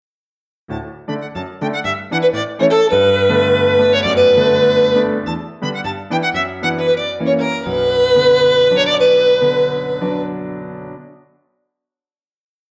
Klavier und Violine
Anhänge KlavierVioline2.mp3 497,5 KB · Aufrufe: 190
Also wenn ich die Instrumente wieder ein Stück auseinanderziehe, dann verlieren die halt viel Präzision und Raumtiefe. Außerdem hat die Violine viele Out-Of-Phase-Anteile, die in mono den Klang versauen. Hatte dann ursprünglich einfach nur den linken Kanal verwendet.